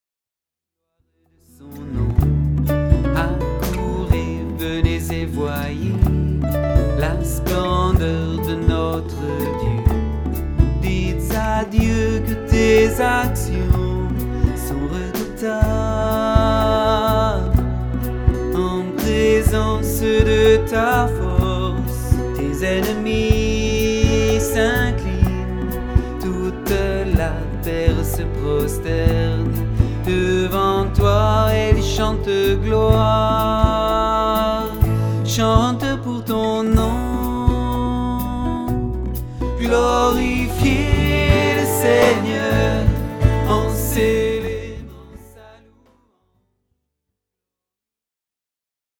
Usage : Pop louange
MIDI 4 voix